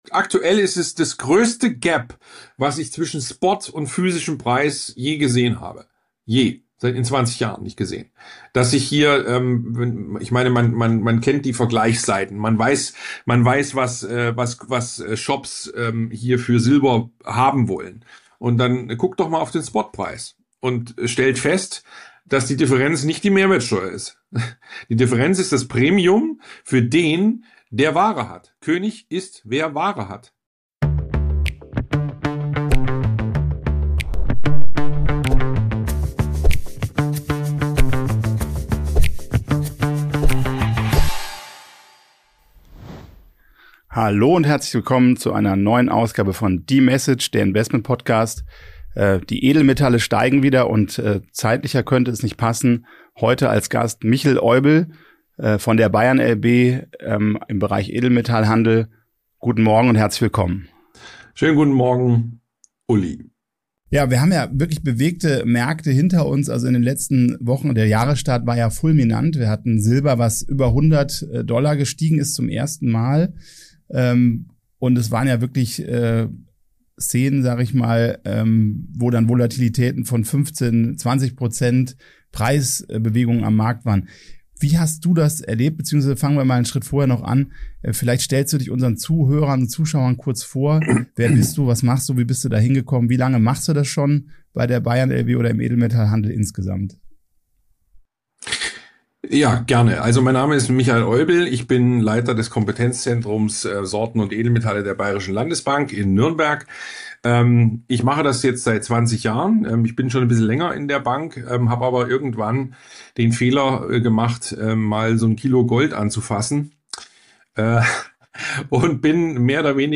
Ein Gespräch über Liquidität, Geopolitik und die Zukunft des Geldes.